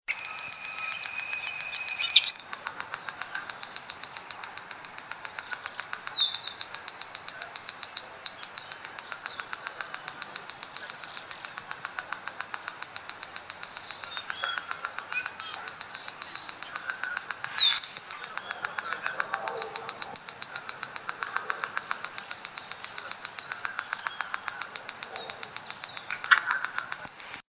Bate-bico (Phleocryptes melanops)
Nome em Inglês: Wren-like Rushbird
Fase da vida: Adulto
Localidade ou área protegida: Reserva Ecológica Costanera Sur (RECS)
Condição: Selvagem
Certeza: Gravado Vocal